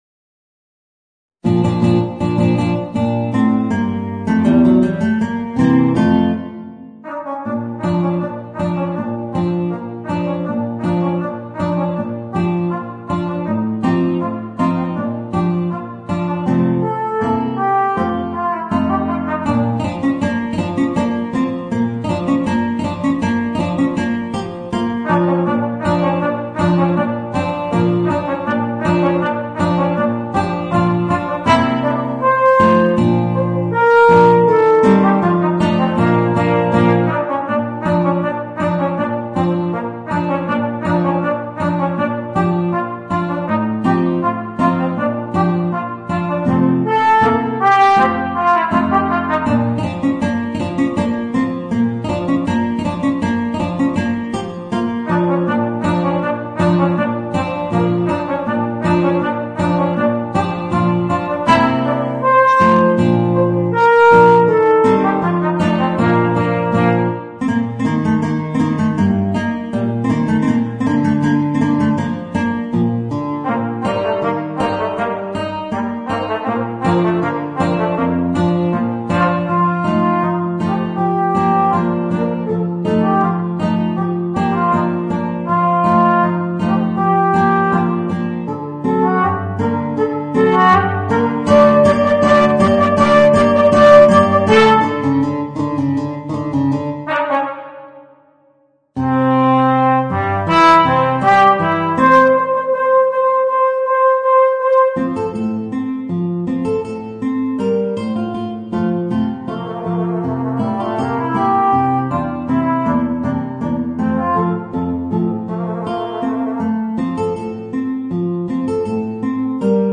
Voicing: Guitar and Alto Trombone